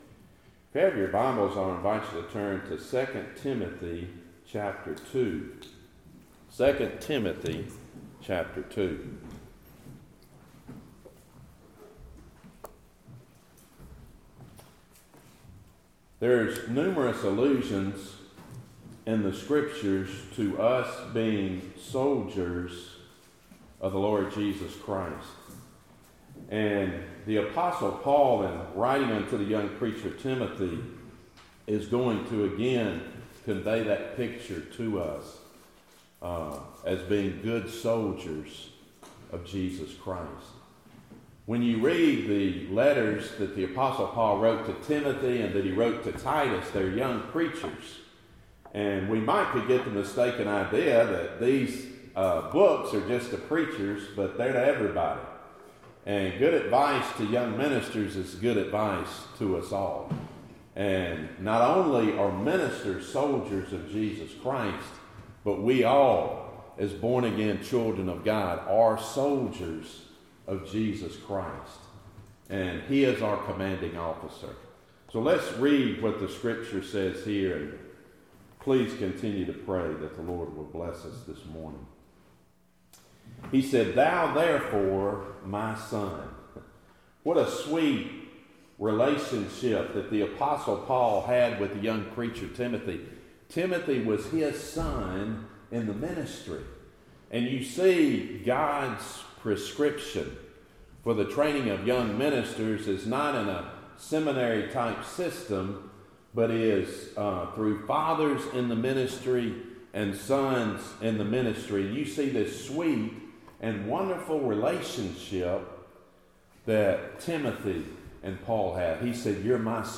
Topic: Sermons Book: 2 Timothy